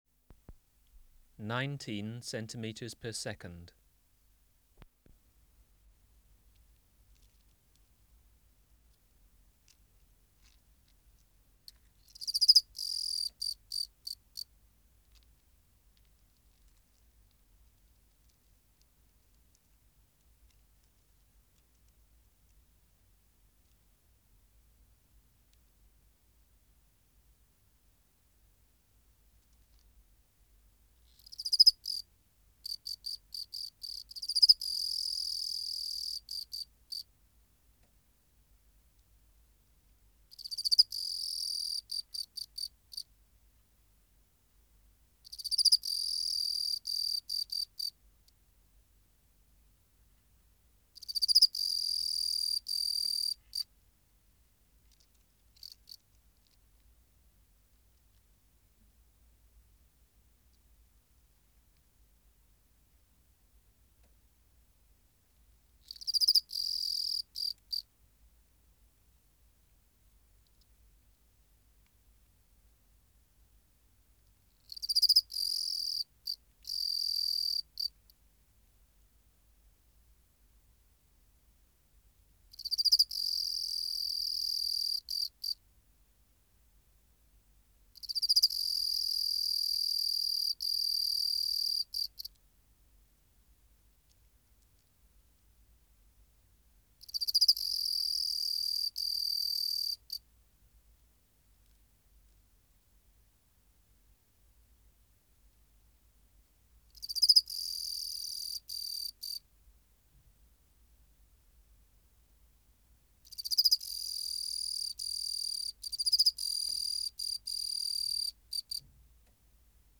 394:12 Teleogryllus sp.(364b) | BioAcoustica
Recording Location: BMNH Acoustic Laboratory
Reference Signal: 1 kHz for 10 s
Substrate/Cage: Recording cage Biotic Factors / Experimental Conditions: Female present in cage-courtship?
Microphone & Power Supply: Sennheiser MKH 405